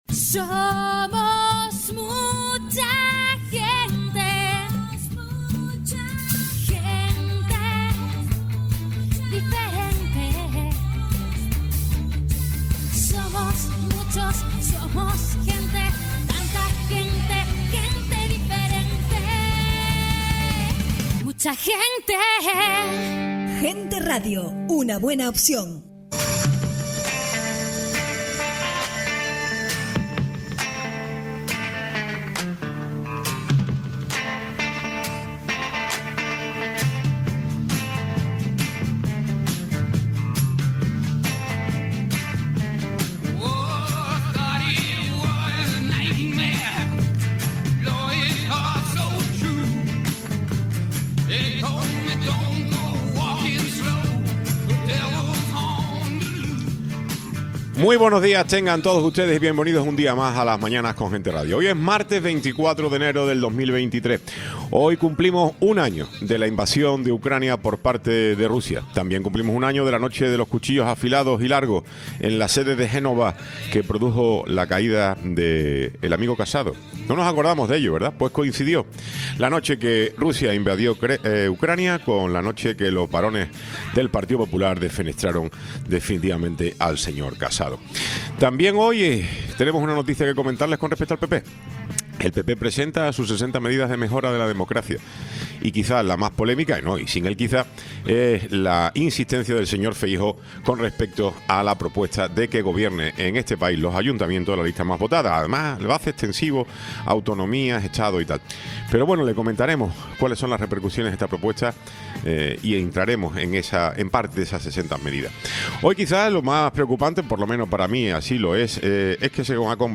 Tiempo de entrevista